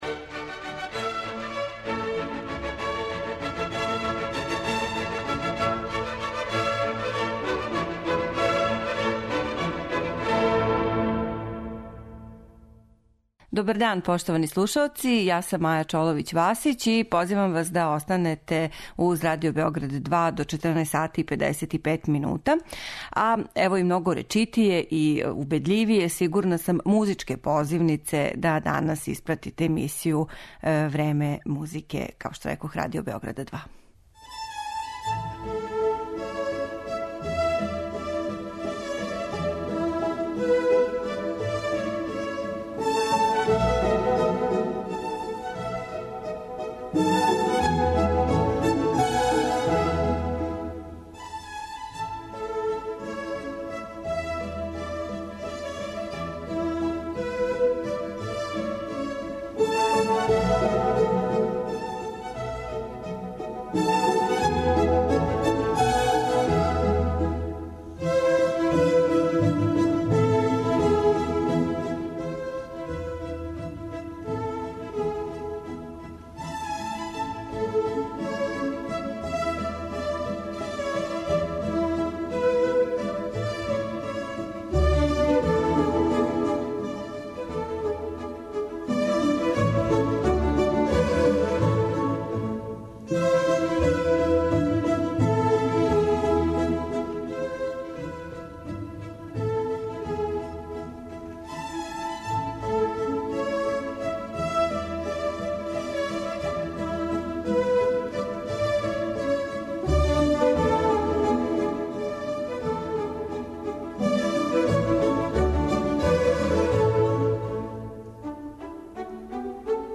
Емисију у којој ћете бити у прилици да чујете фрагметне из Бокеринијевих познатих дела